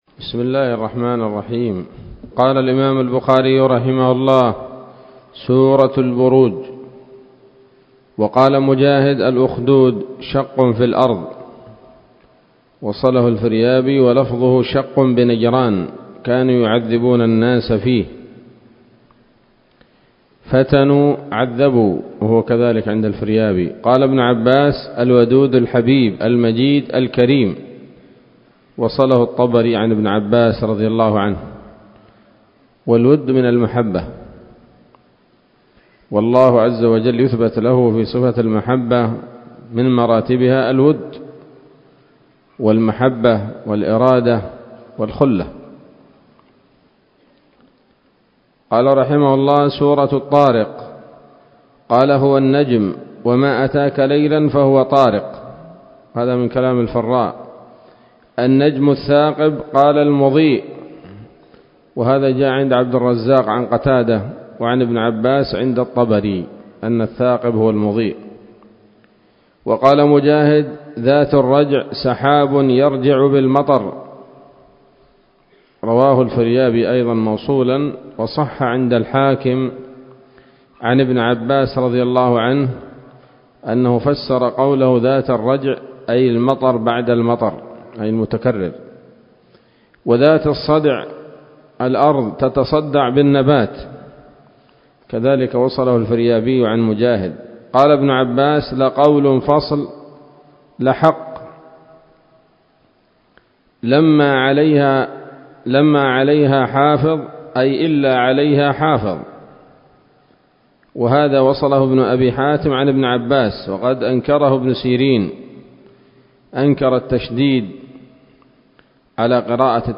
الدرس الرابع والثمانون بعد المائتين من كتاب التفسير من صحيح الإمام البخاري